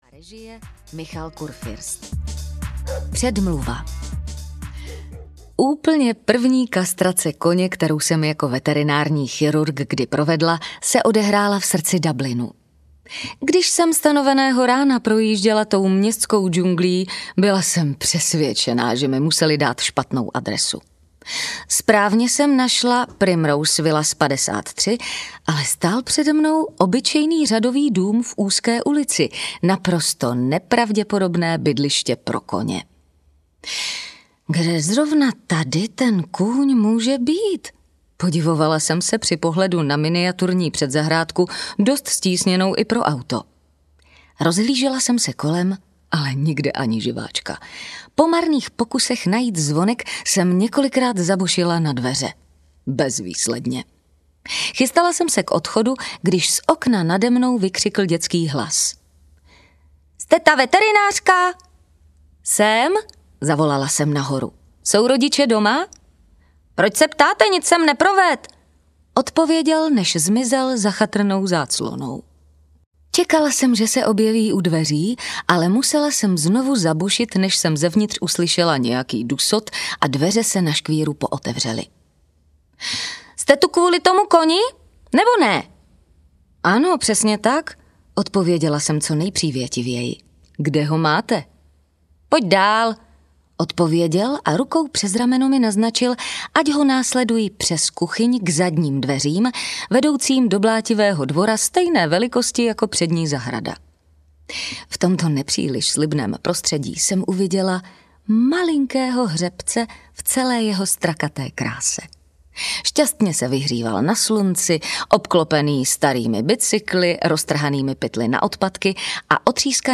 Veterinářka audiokniha
Ukázka z knihy
Nejen že musí pomoci zvířatům od jejich neduhů, ale musí se vypořádat i s „lidskými“ nástrahami v podobě dohazovačských matek nebo šovinistických farmářů, nemluvě o chlapících, kteří se neustále ptají: „A kde je ten opravdickej veterinář?“Humorem a energií nabitou sbírku příhod jedné neohrožené mladé irské veterinářky čte známá herečka a slavná a oceněná dabérka Jana Štvrtecká.
• InterpretJana Štvrtecká